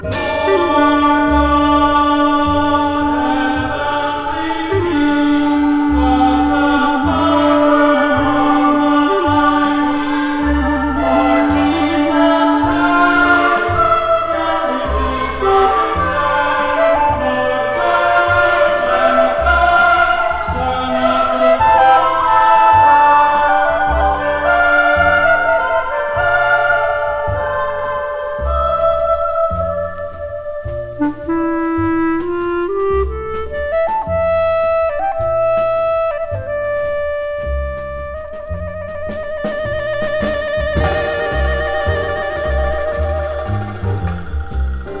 クールとモーダルと神秘の三拍子を揃った、様々な角度から音楽を楽しみ感じさせる1枚。
Jazz, Classical, Modal　USA　12inchレコード　33rpm　Mono